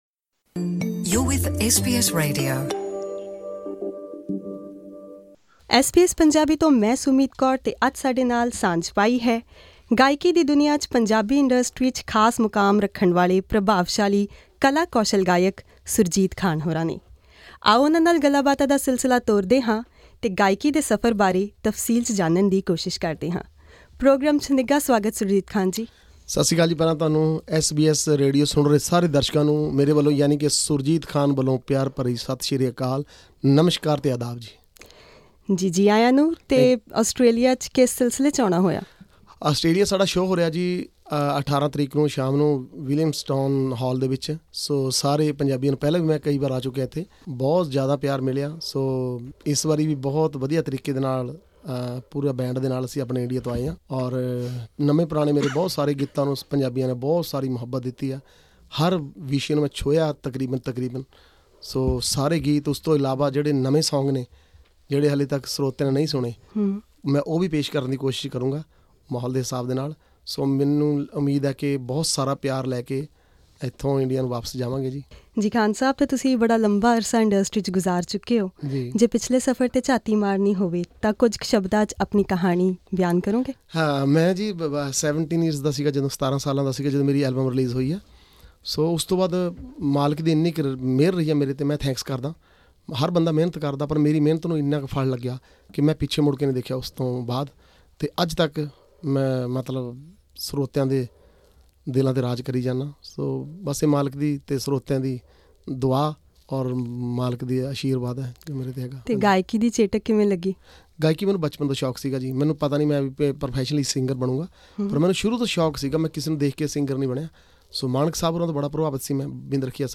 Famous for his folk songs, Surjit Khan takes a trip down memory lane as he reminisces his musical journey, which he embarked on as a teenager. Sitting in the SBS studio, the singer touring Melbourne spoke passionately about how his career shaped his life.